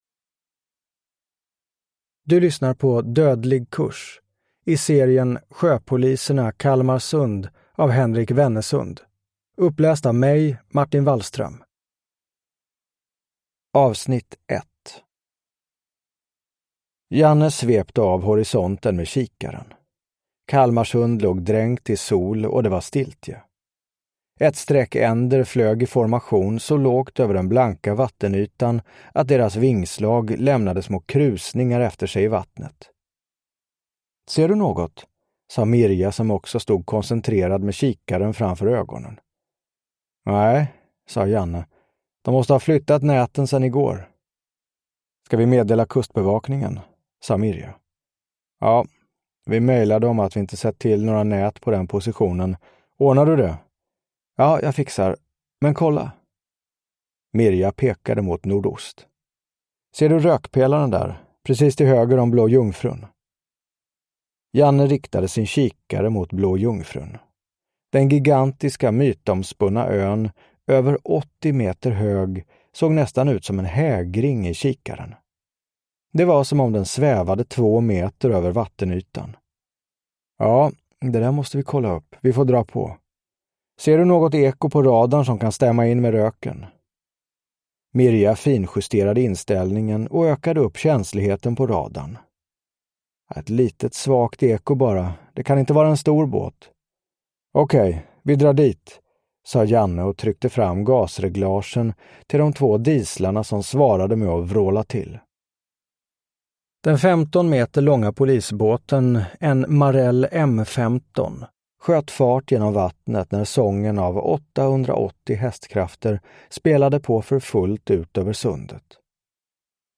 Dödlig kurs (ljudbok) av Henrik Wennesund